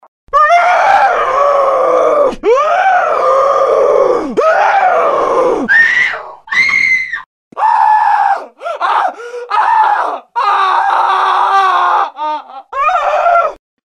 Здесь вы можете слушать и скачивать аудиофайлы, создающие атмосферу таинственности и страха. Подборка включает различные вариации звуков – от шепота до жуткого смеха.